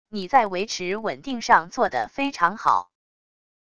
你在维持稳定上做的非常好wav音频生成系统WAV Audio Player